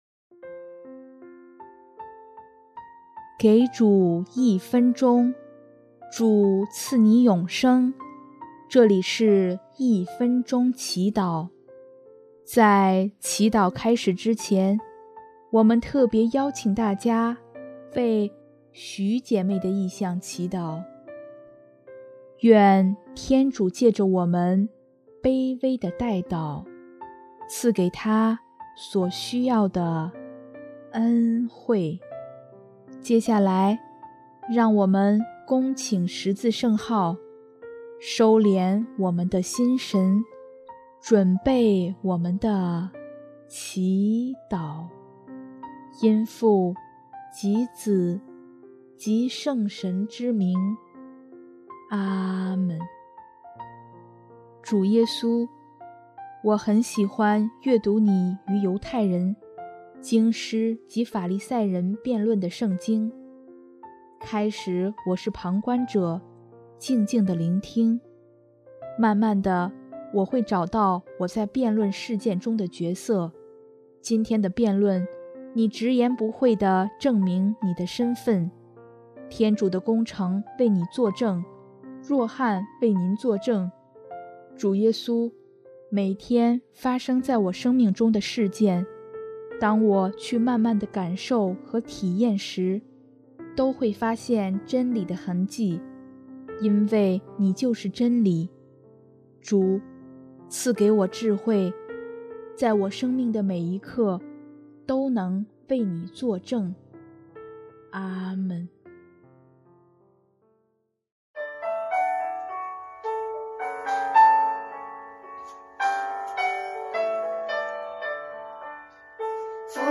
【一分钟祈祷】|4月3日 为真理做证
音乐 ：第四届华语圣歌大赛参赛歌曲《天国的奥秘》